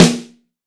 Index of /90_sSampleCDs/AKAI S6000 CD-ROM - Volume 3/Snare1/PICCOLO_SN